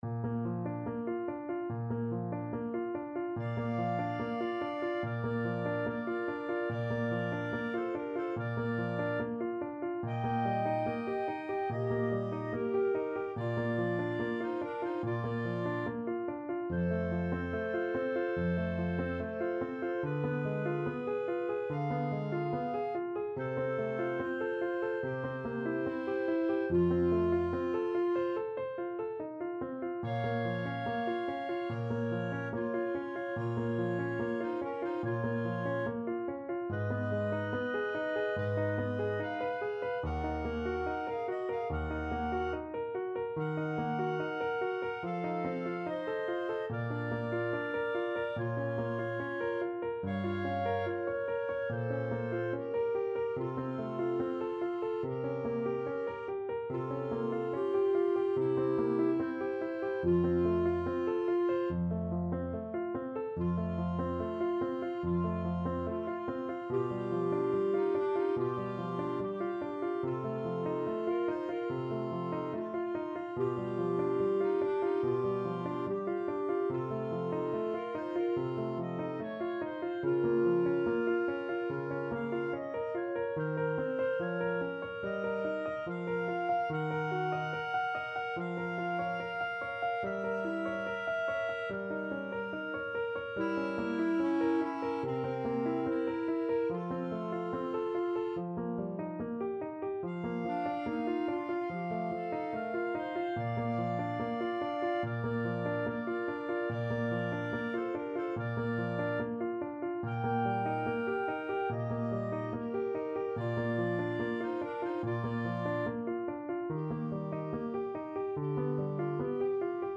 4/4 (View more 4/4 Music)
Andante =72
Classical (View more Classical Clarinet Duet Music)